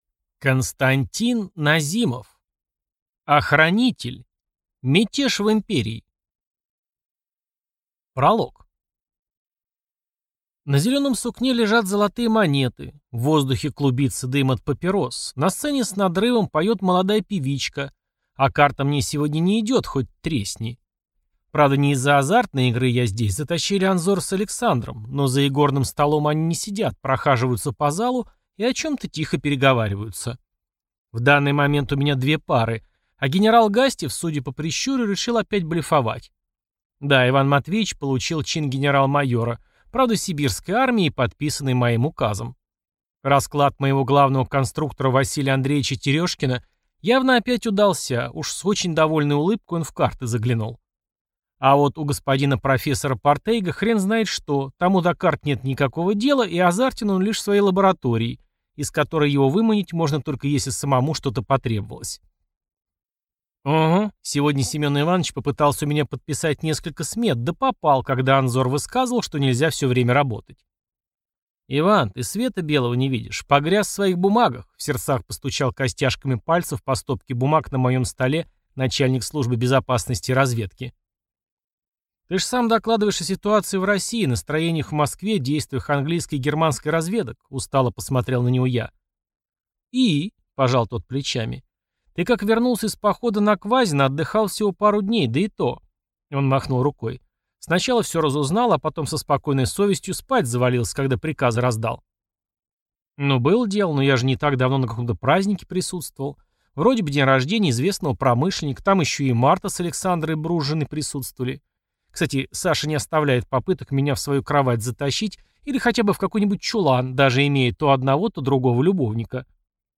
Аудиокнига Охранитель. Мятеж в империи | Библиотека аудиокниг